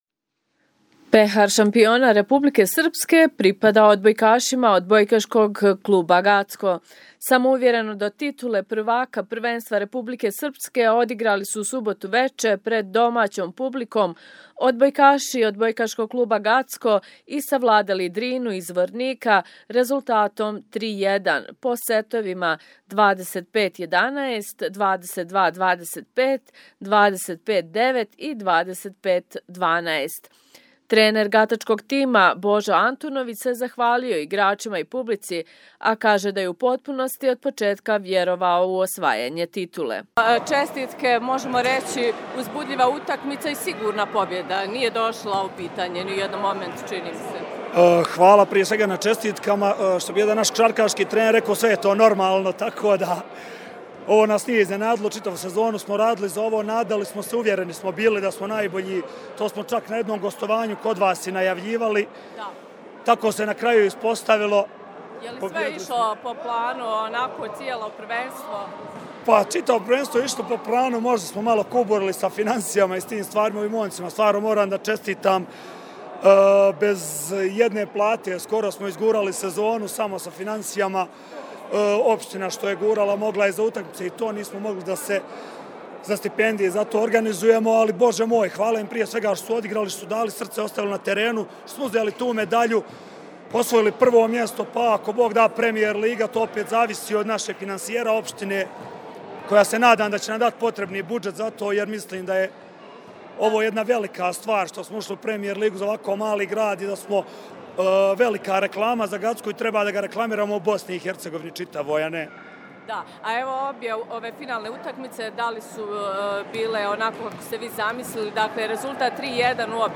Izvjestaj-finale-odbojkasi-titula.mp3